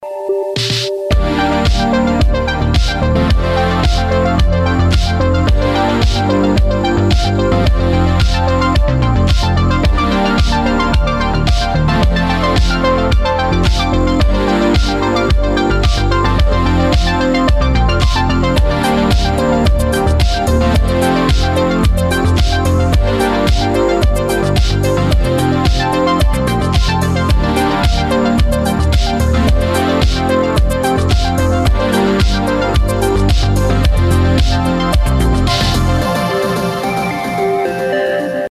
Vaporwave, nu disco, future funk, synthwave